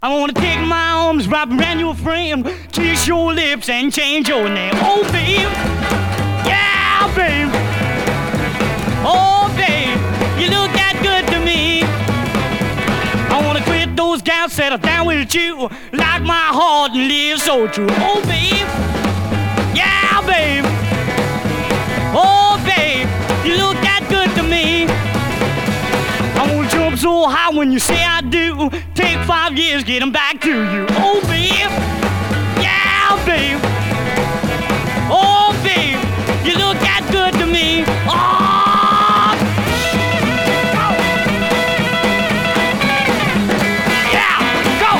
Rock & Roll, Rockabilly　Germany　12inchレコード　33rpm　Mono